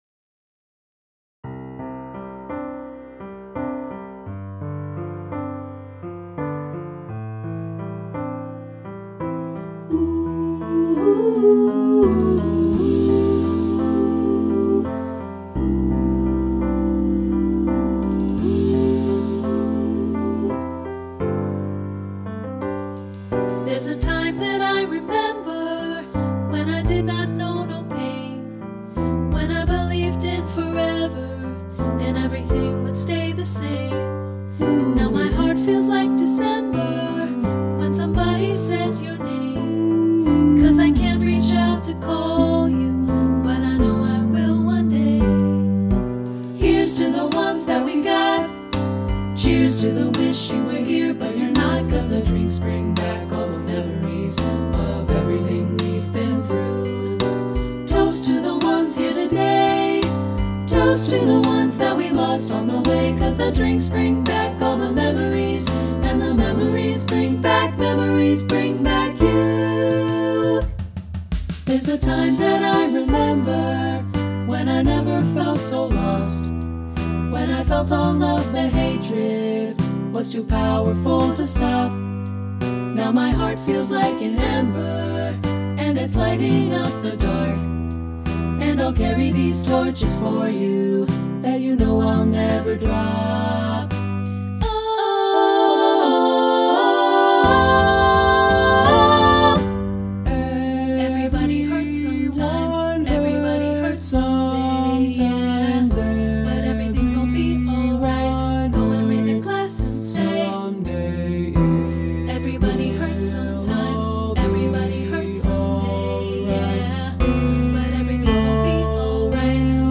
contemporary choral SATB arrangement